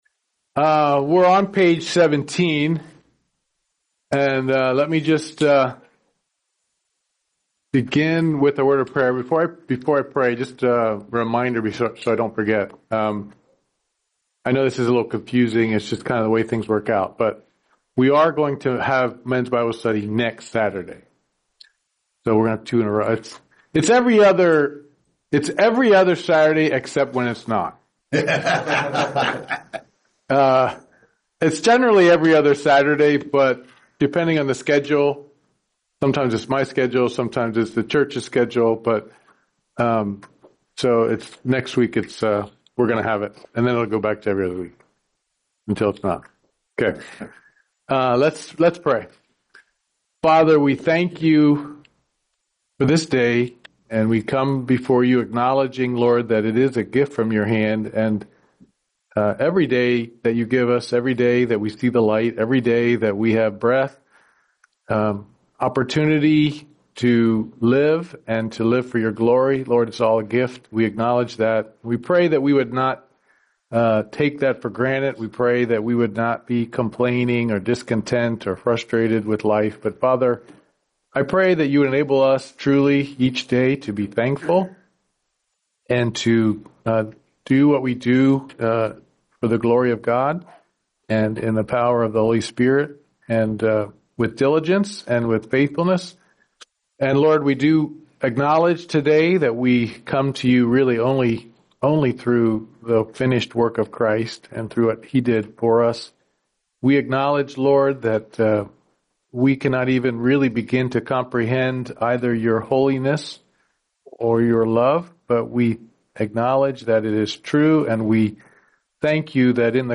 Men's Bible Study